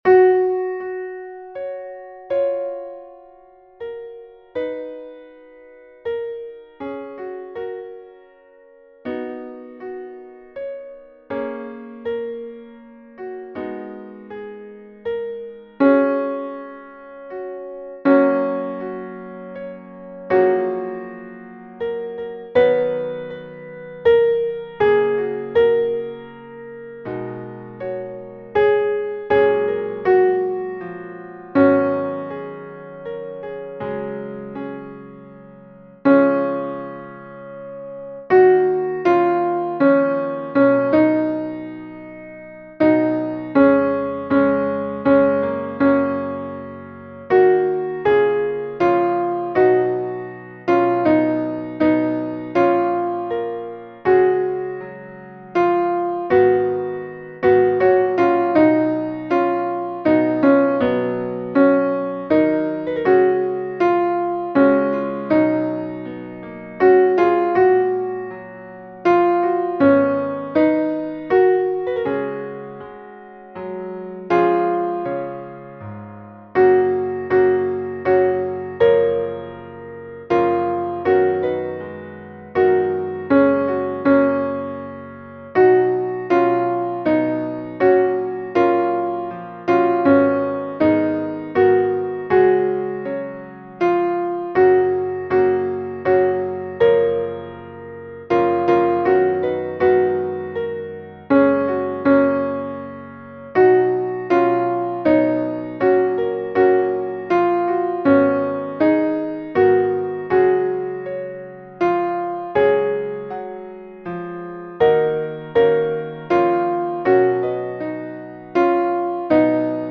Versions piano